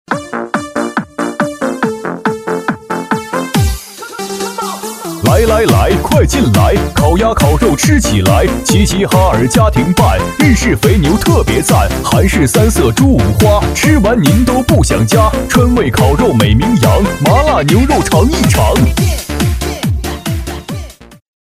男C16-喊麦 - 烤吖烤肉
男C16-年轻质感 激情力度
男C16-喊麦 - 烤吖烤肉.mp3